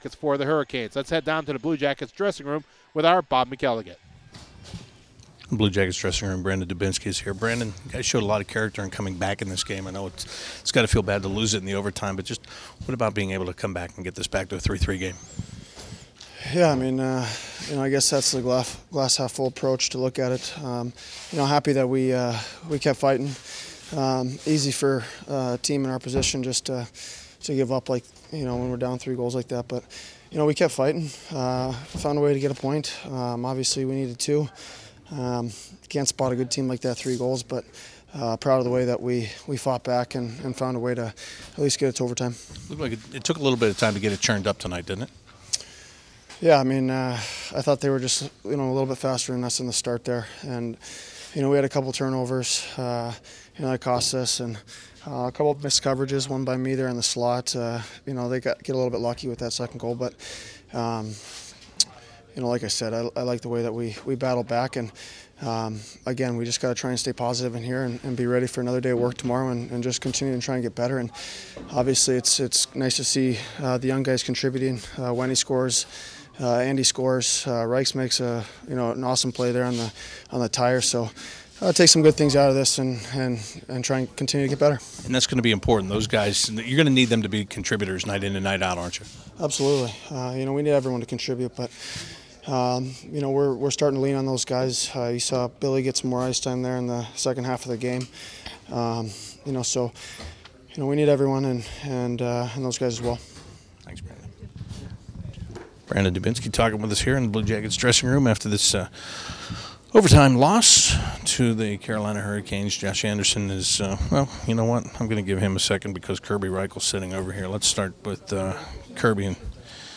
Brandon Dubinsky, Kerby Rychel, Josh Anderson and Seth Jones talk about their 4-3 overtime loss to the Carolina Hurricanes